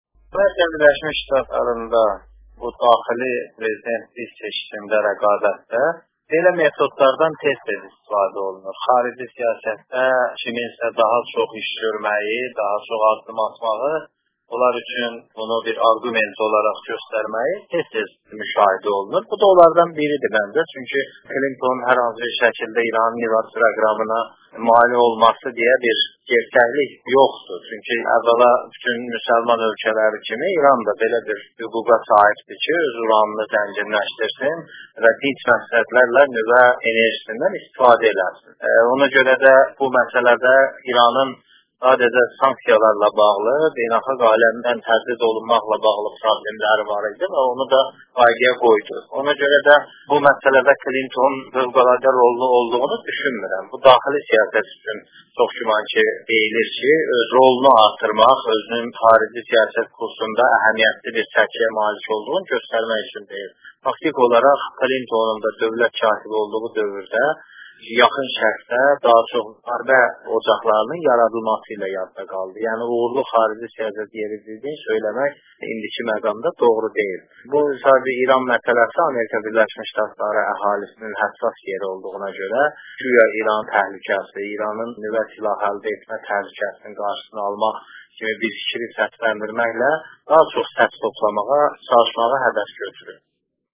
Bunu Az. R. deputatlarından olan Fazil Qəzənfərolğlu SəhərBeynəlxalq Telekanalının Azəri Radiosuna eksklüziv müsahibə verərəkən söyləyib, Bu haqda ətrafı məlumatı audio materilada dinləyə bilərsiniz.